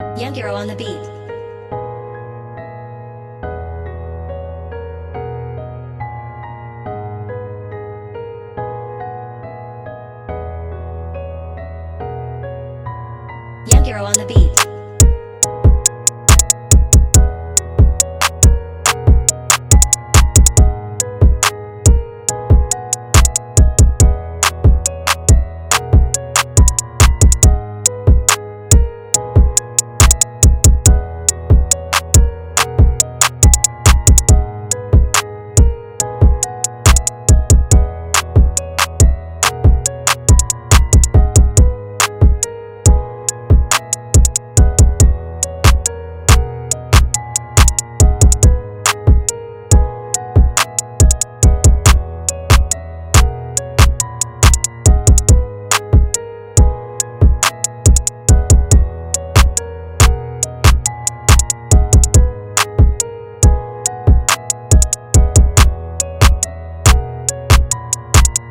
Type Beat, Sample, Loop
Жанр: Rap, Trap, Hip-Hop